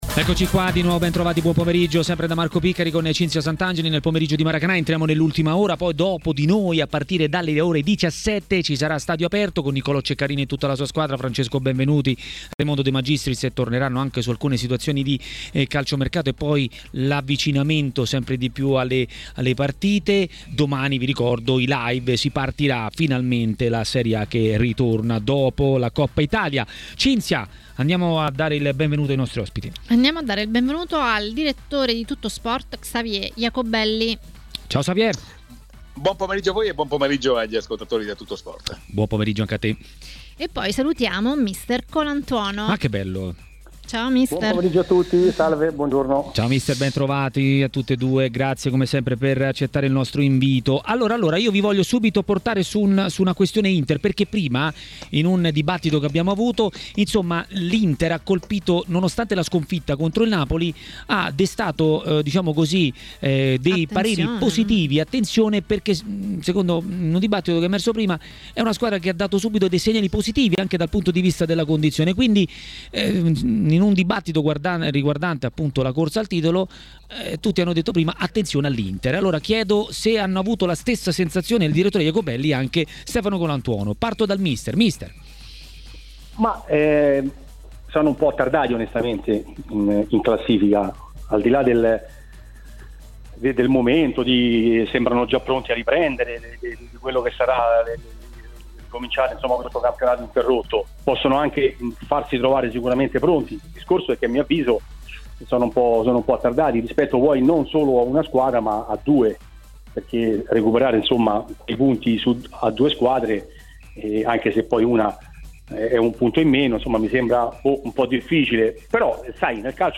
A parlare della ripresa del campionato a TMW Radio, durante Maracanà, è mister Stefano Colantuono.